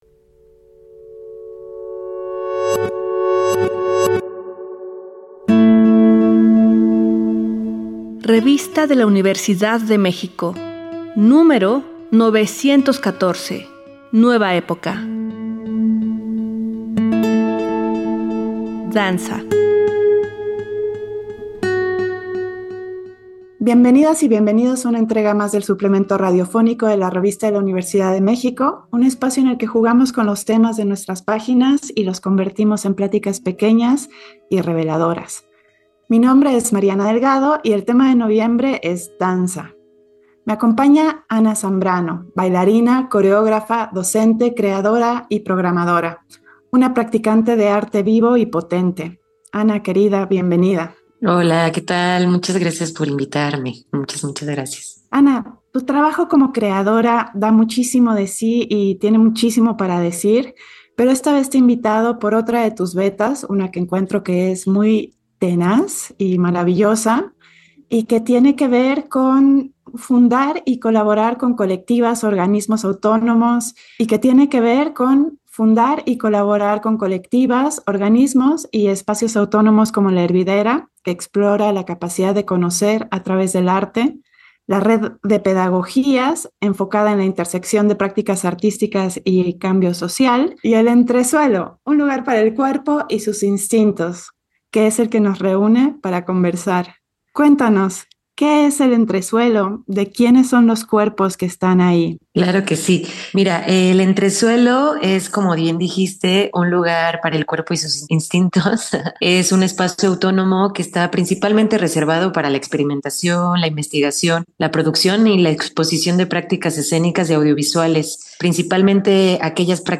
El centro de esta conversación es el desafío de colectivas, organismos y espacios autónomos como El Entresuelo, un lugar para el cuerpo y sus instintos que está dedicado a la producción, investigación y visibilización de prácticas escénicas y audiovisuales comprometidas con la búsqueda de nuevos horizontes creativos.
Fue transmitido el jueves 22 de noviembre de 2024 por el 96.1 FM.